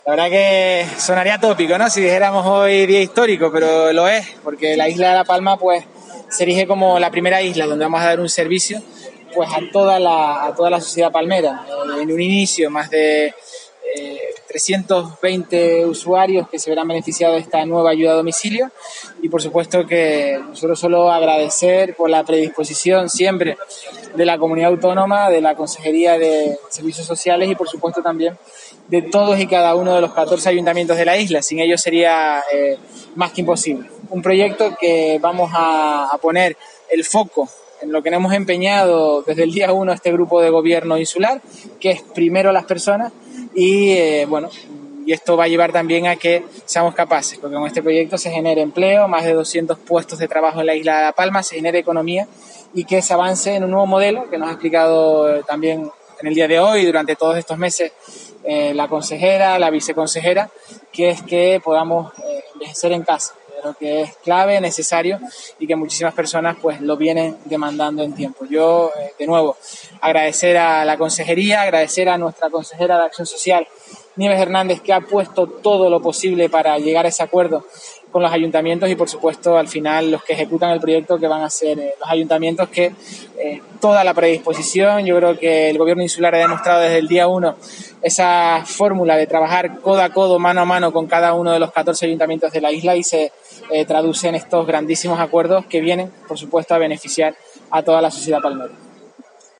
Presidente del Cabildo de La Palma, Mariano Zapata.mp3